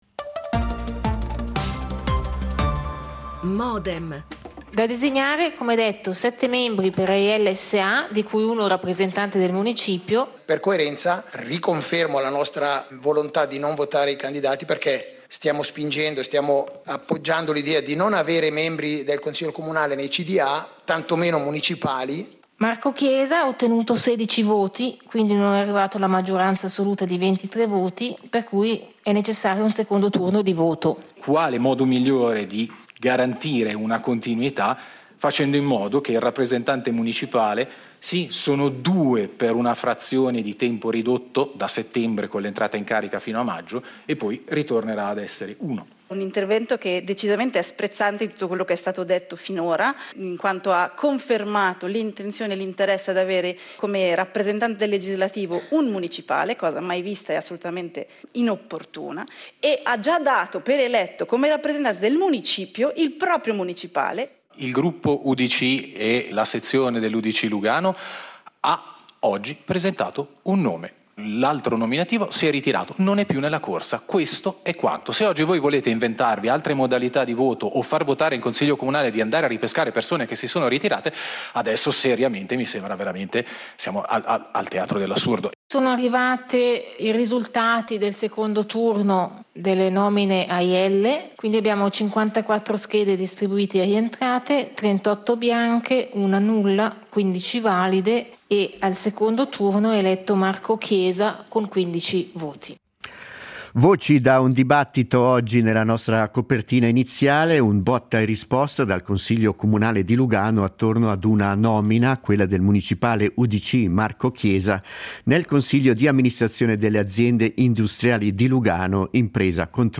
Ne discutono nella puntata di Modem cinque consiglieri comunali:
L'attualità approfondita, in diretta, tutte le mattine, da lunedì a venerdì